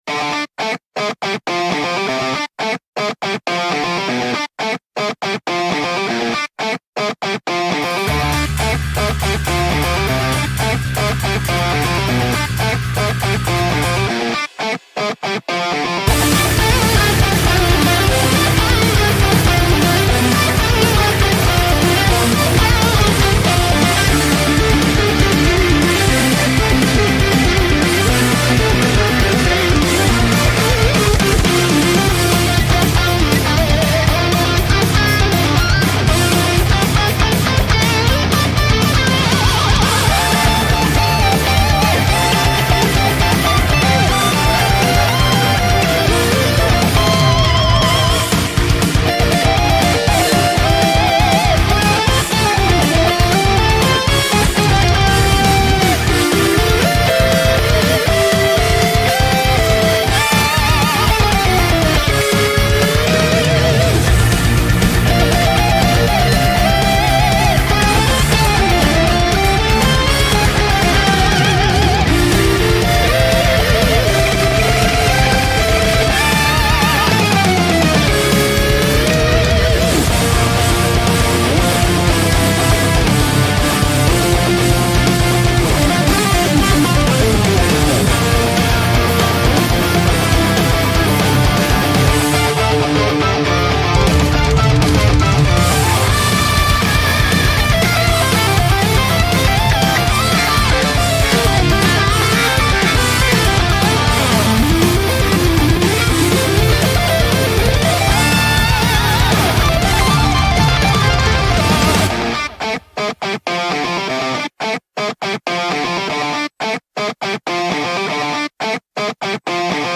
BPM120-240
Audio QualityPerfect (Low Quality)